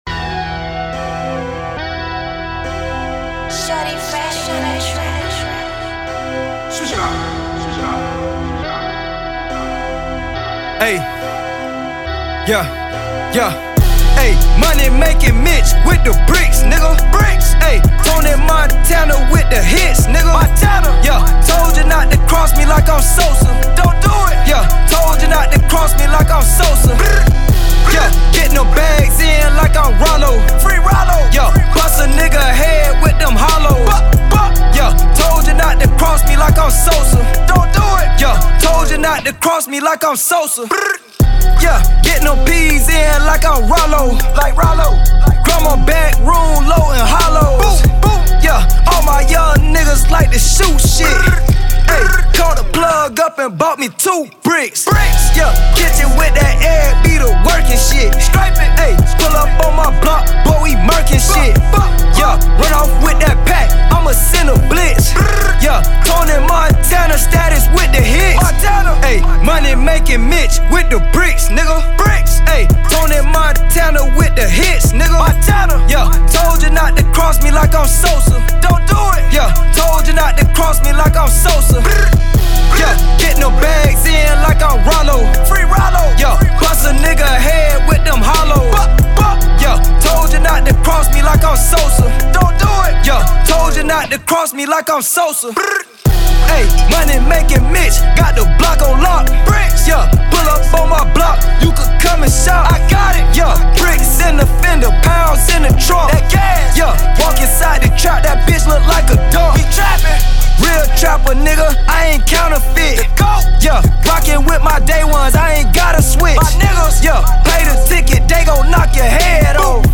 Delivering a gutter sound